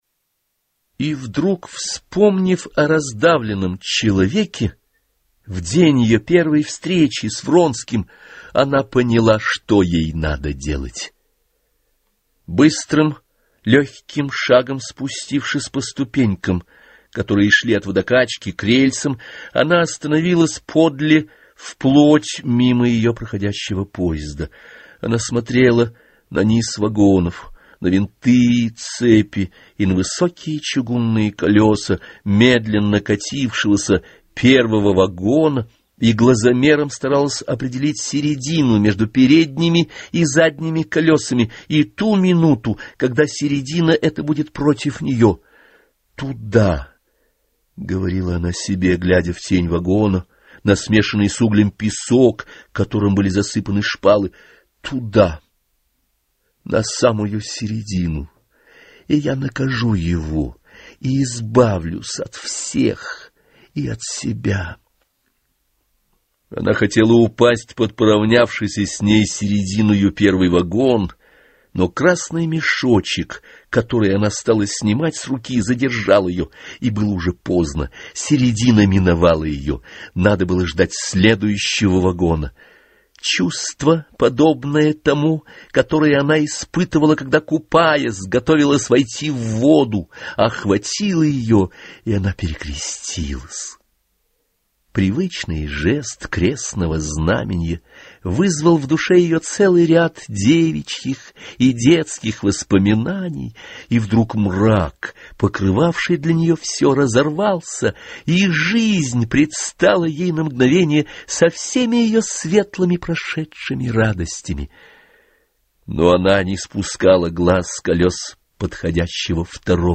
Anna_karenina_audbook.mp3